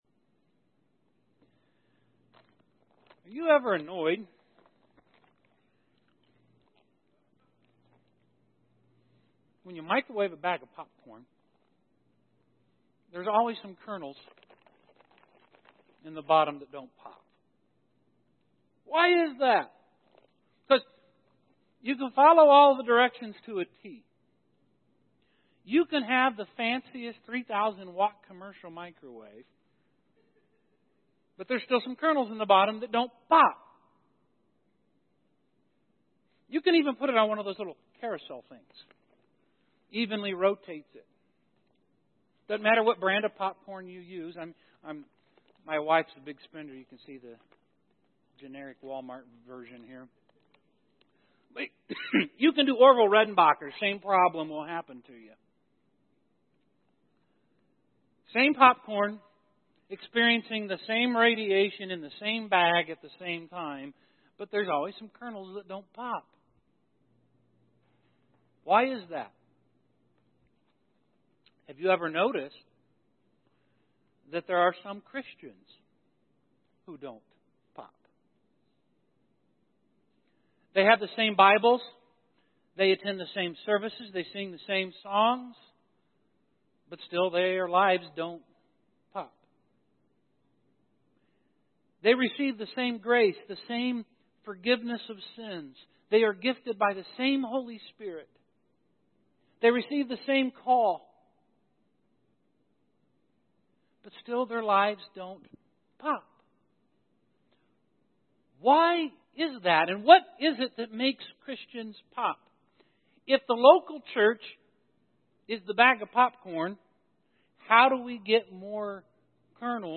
Romans Audio Sermon Save Audio Save PDF We have a great misconception concerning worship.